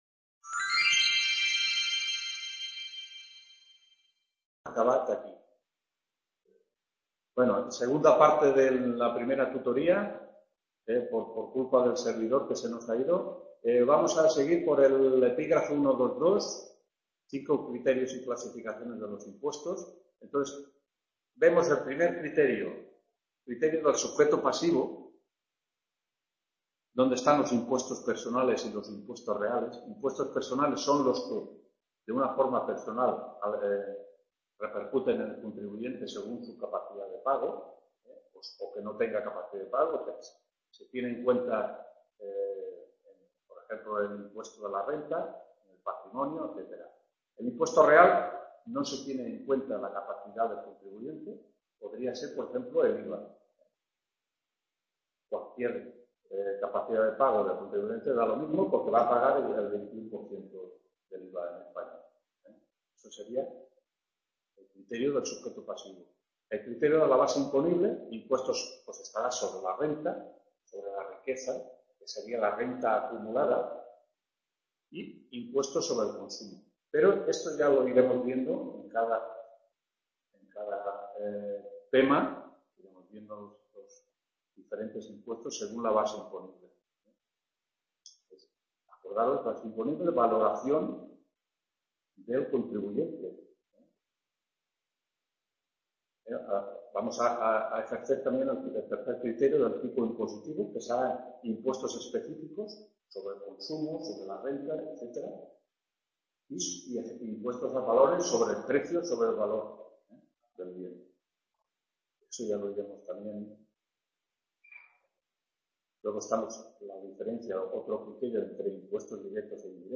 1ª TUTORÍA (IIª PARTE) TEORÍA DE LOS INGRESOS PÚBLICOS… | Repositorio Digital